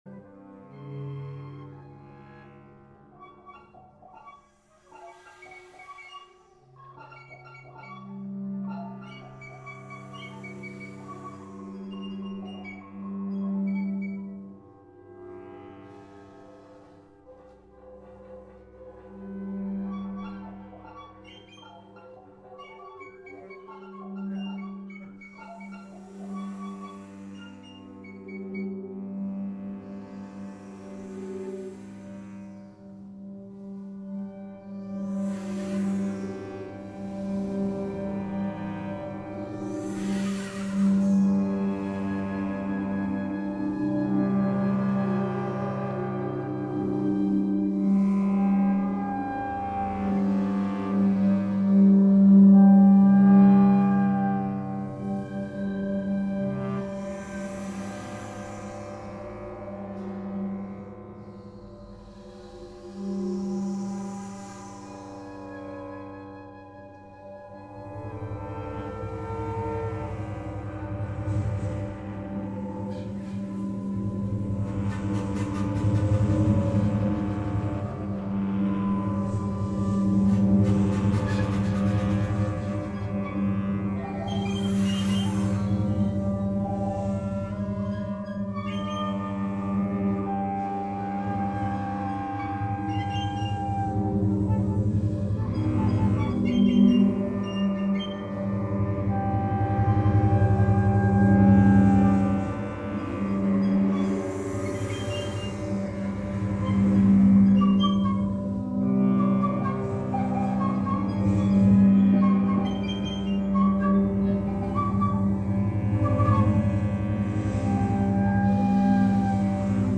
Live from the International Computer Music Conference
Recorded from the Wave Farm Transmit Parter stream.